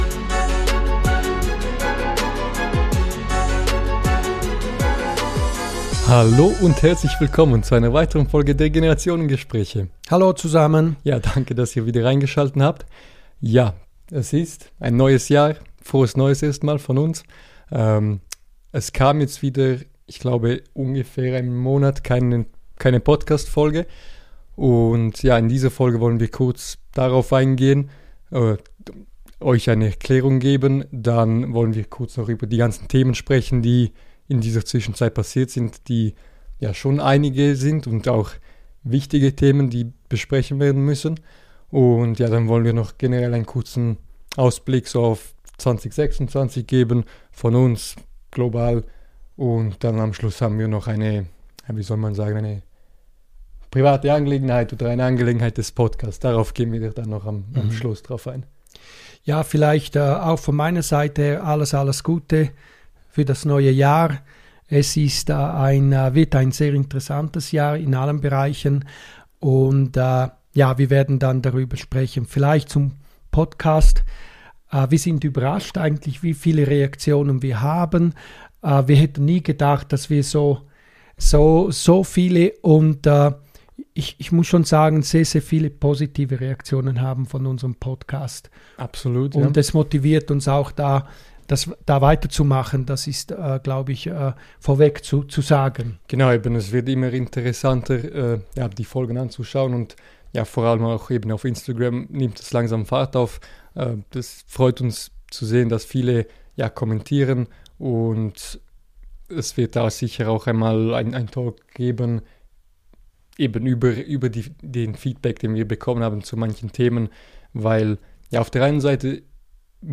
Heute sprechen wir über die wichtigsten Ereignisse, die in der Zwischenzeit passiert sind: die tragische Brandkatastrophe in Crans-Montana am Jahresanfang, die Silvesternacht in Berlin, den massiven Stromausfall in Berlin durch einen Sabotageakt und die dramatische militärische Operation der USA gegen Venezuela mit der Gefangennahme von Präsident Maduro. Wir ordnen ein, was das alles für Europa, Deutschland und die Welt bedeutet – sachlich, kritisch und im direkten Austausch.